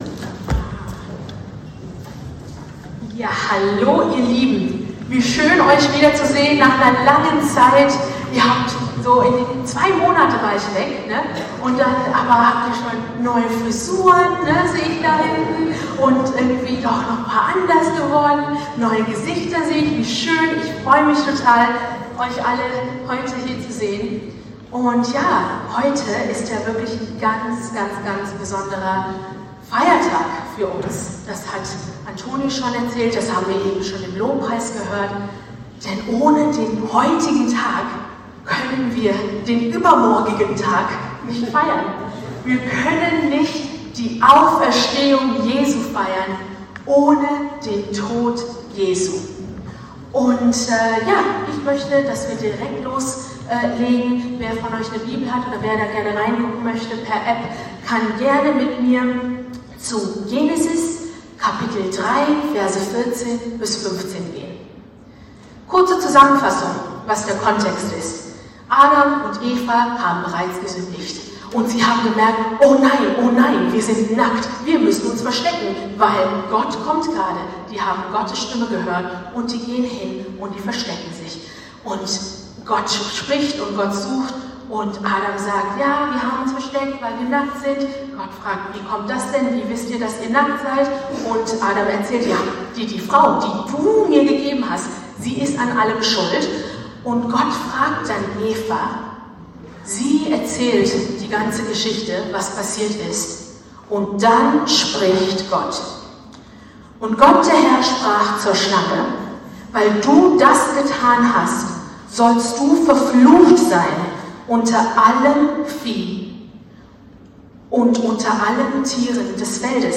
Karfreitag Gottesdienst der International Church Bonn